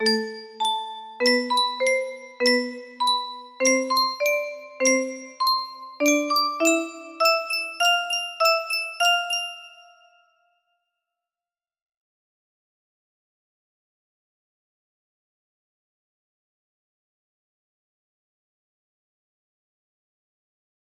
JM music box melody
Full range 60